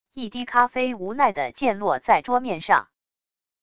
一滴咖啡无奈地溅落在桌面上wav音频